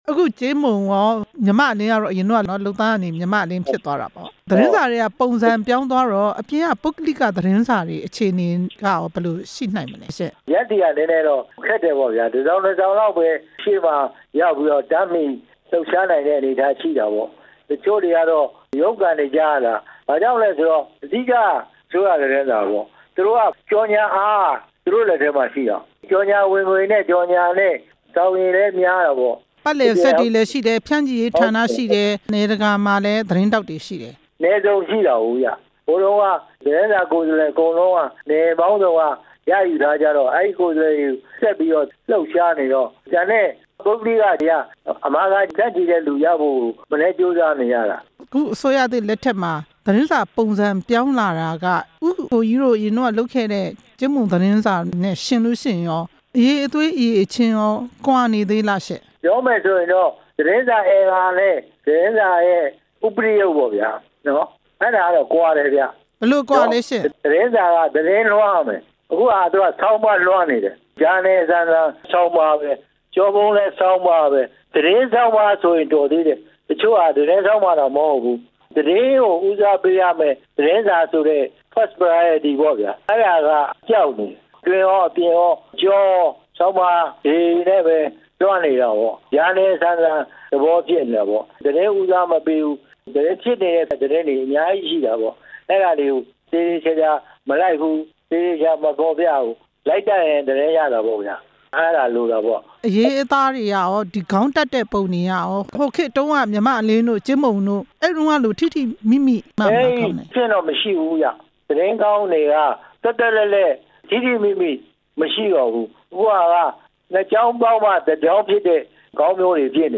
အစိုးရသစ်လက်ထက် ပုဂ္ဂလိက သတင်းစာတွေ ရပ်တည်ရမယ့် အခြေအနေ မေးမြန်းချက်